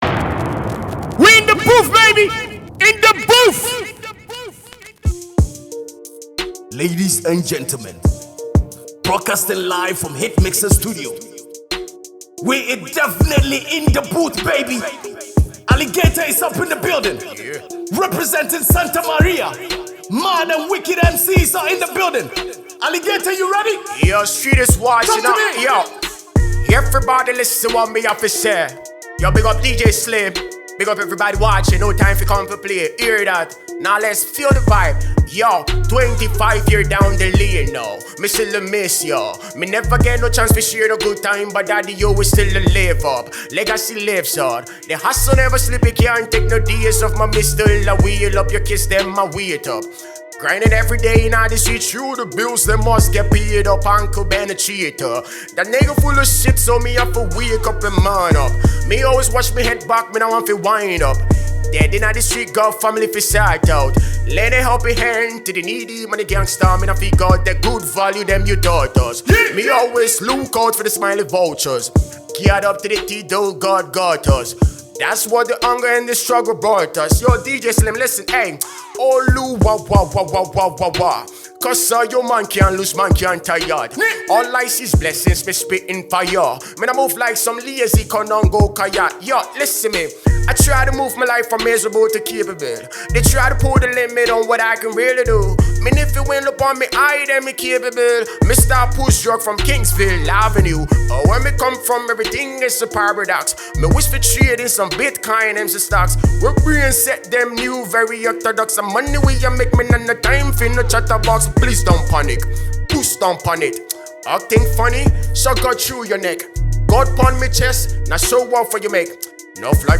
a super talented Ghanaian songwriter, singer, and rapper